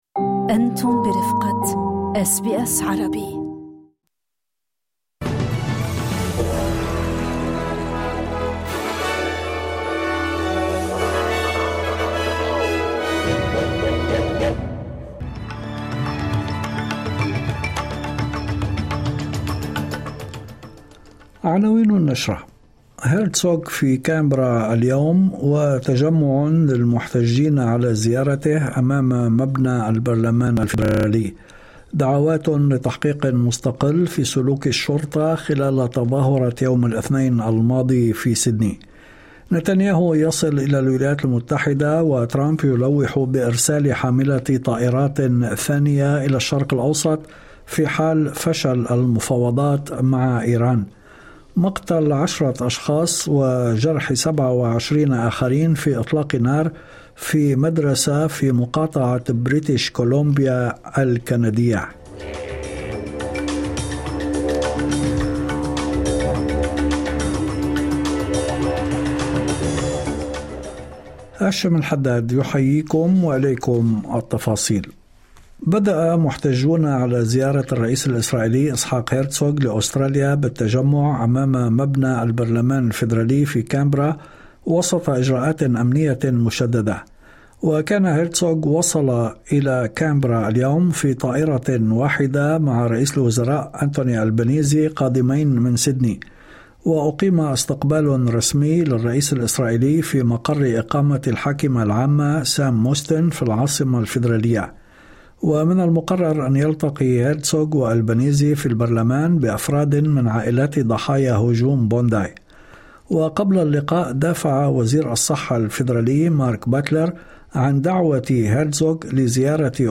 نشرة أخبار المساء 11/02/2026 [AI Ad: Arabic]